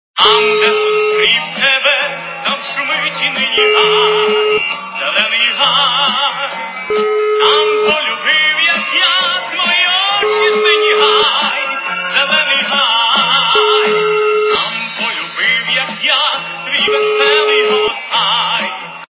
народные